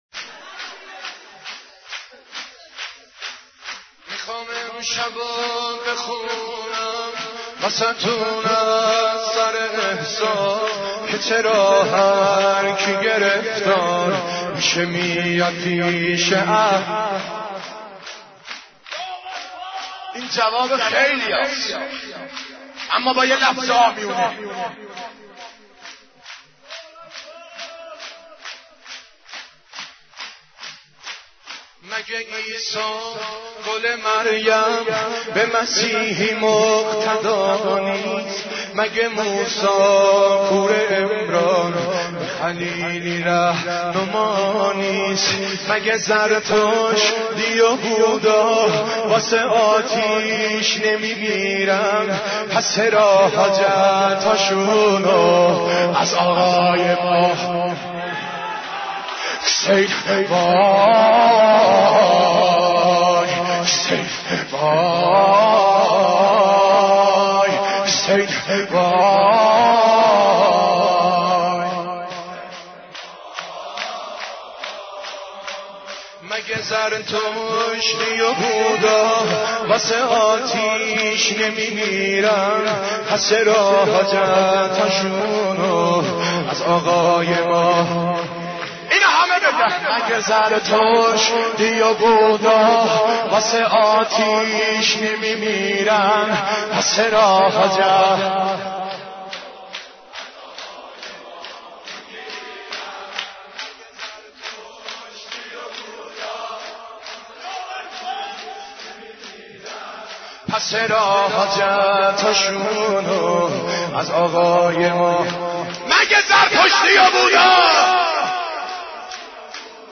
حضرت عباس ع ـ شور 14